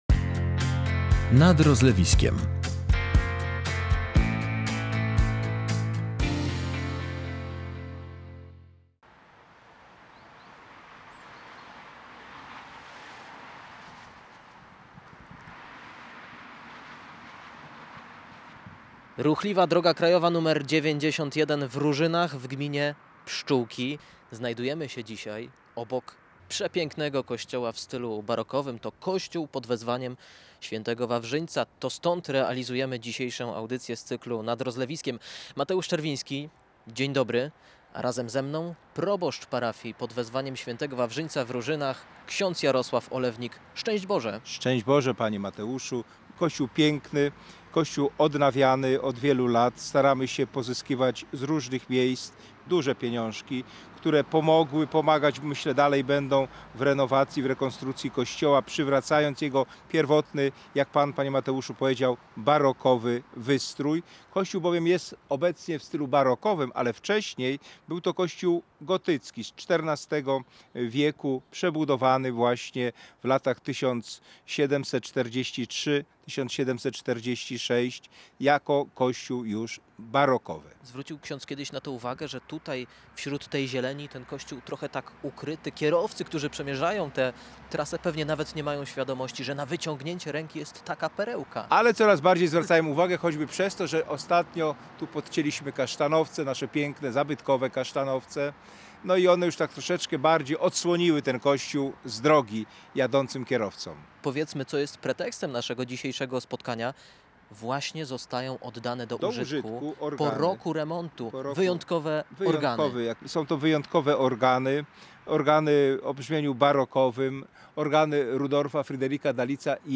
był z mikrofonem podczas odbioru odnowionych organów w Różynach